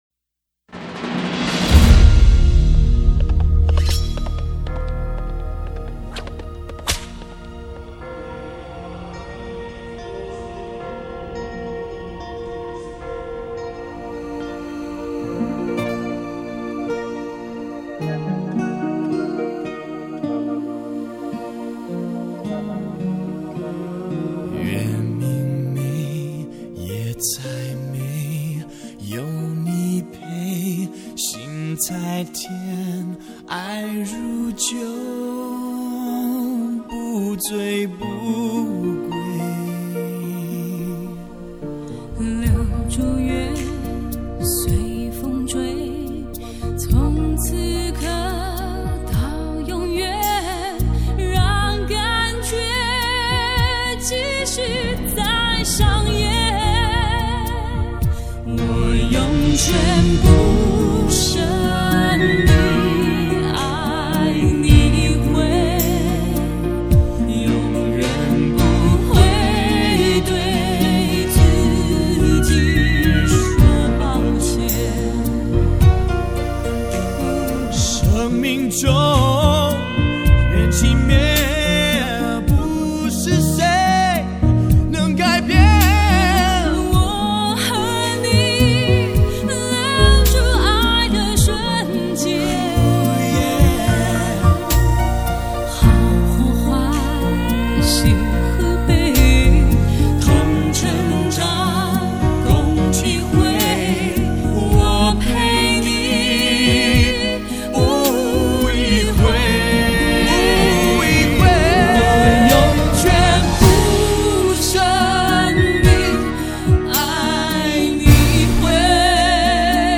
婉转动人的拉丁曲风，紧致的曲式编排，刻划出爱情的一往情深和忠贞不渝。
一首对唱情歌
甩鞭子的声音，还是整个曲子的旋律，都与英雄片的故事结合非常紧密。
整首围绕一个螺旋向上的旋律进行，
极具互补性的嗓音把歌曲层次感表现的淋漓尽致。
很经典的男女对唱情歌，我很喜欢~~~~~~~~~